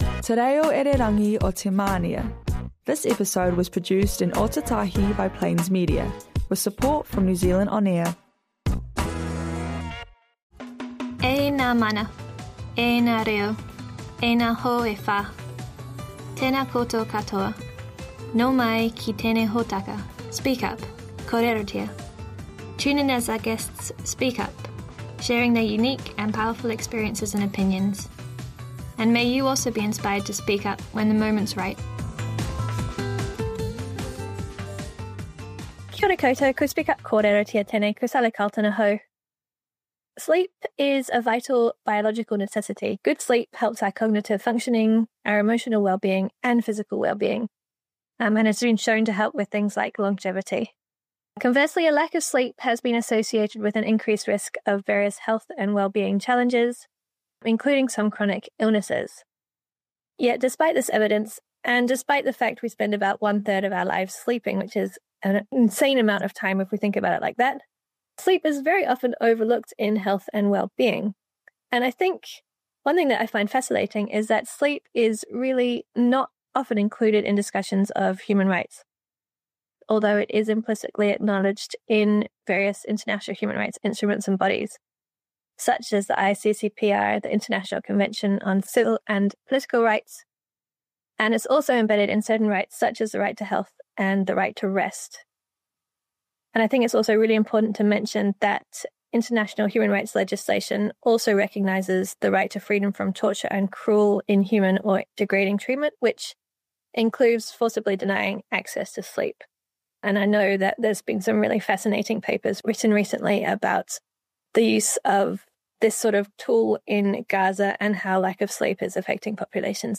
Episode of human rights radio show Speak up - Kōrerotia on the topic of sleep health particularly in young people and inequities with…
Show first aired on 7 July 2025 on Canterbury's Plains FM, made with the assistance of NZ On Air.